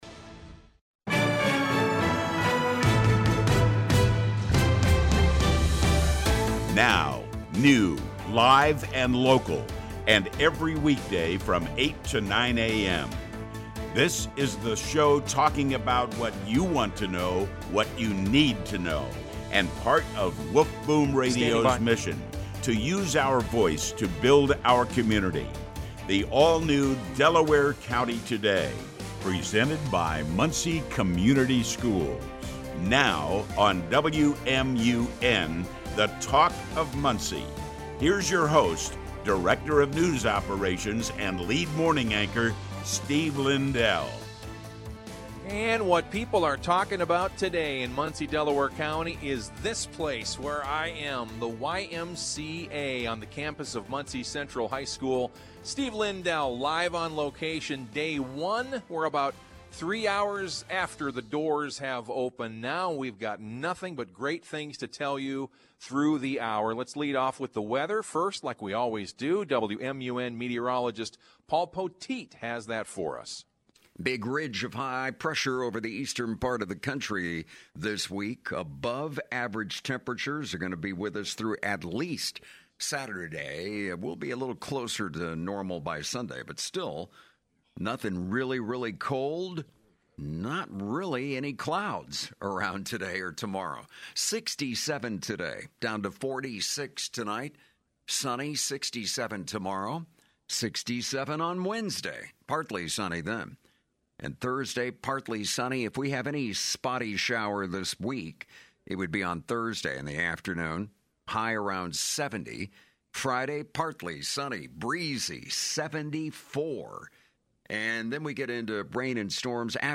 Editor’s note: The audio immediately below is from the Y’s opening day on March 10, 2025. Audio provided by WMUN Radio, Muncie.